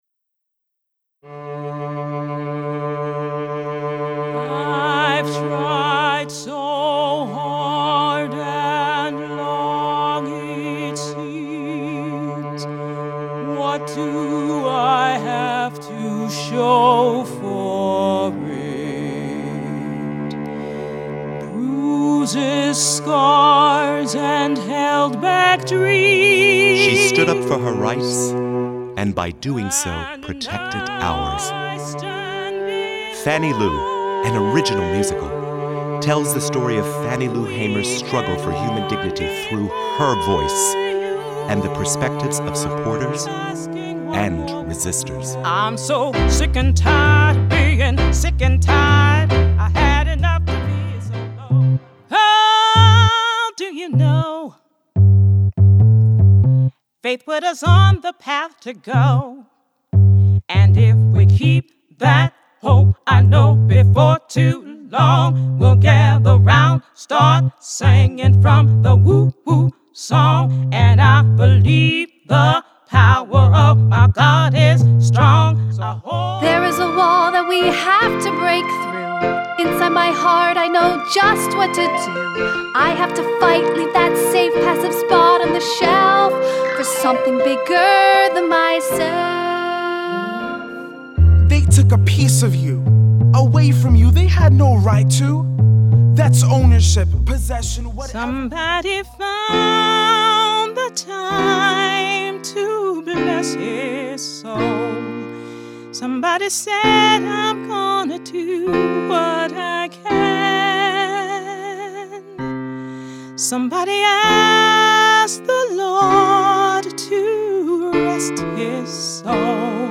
viola solo version